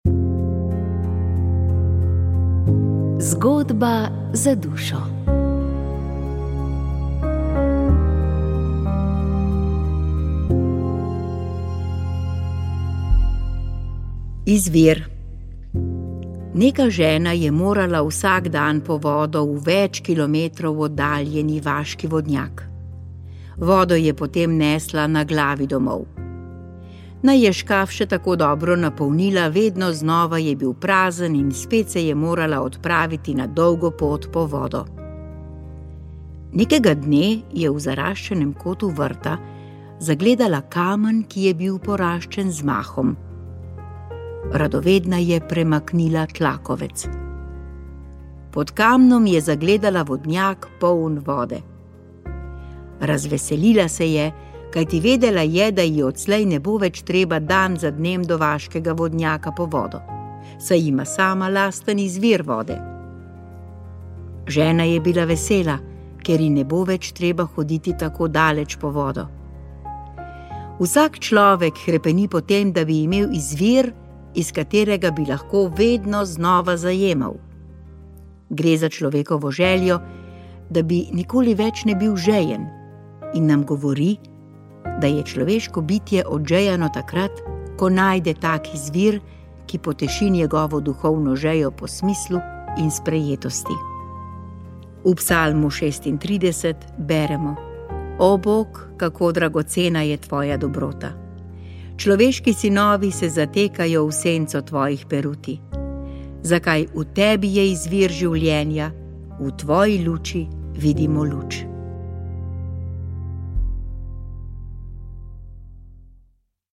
Molil so radijski sodelavci.